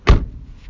汽车门
描述：关上车门
标签： 车门 踩住 汽车